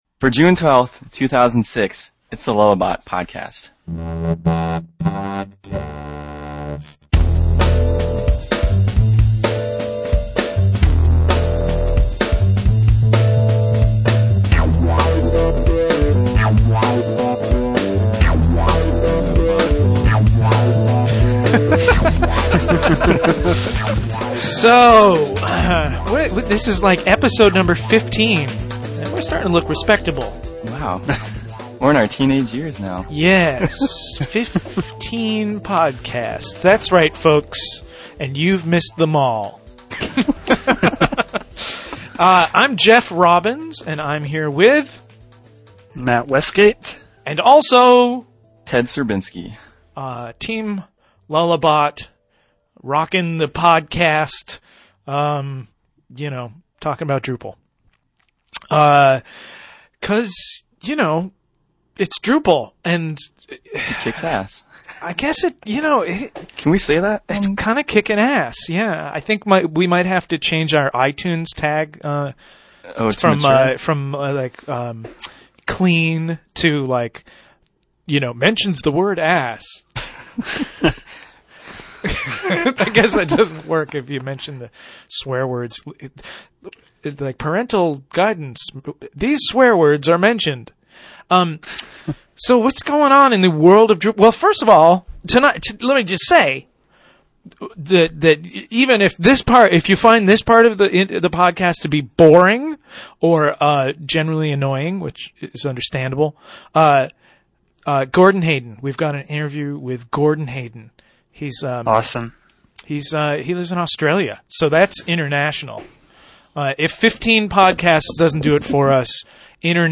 Drupal news and an interview